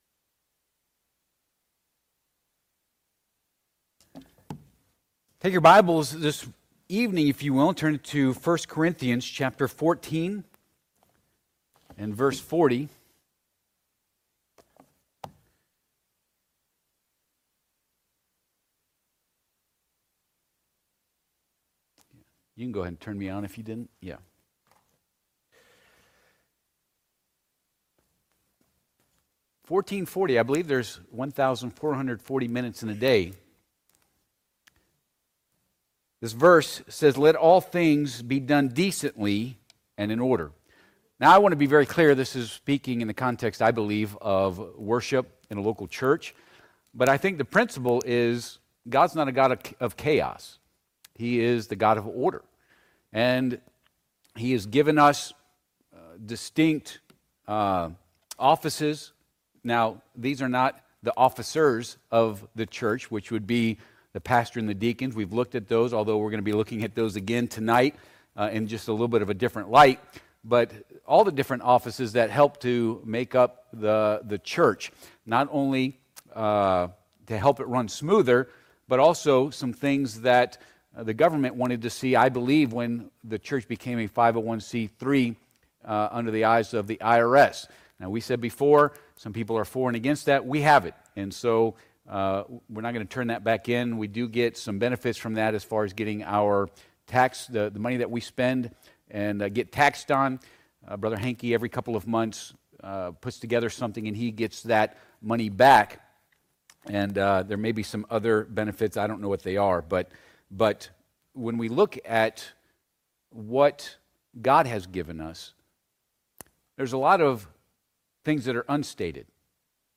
1 Cor. 14:40 Service Type: Sunday PM « Where Was God?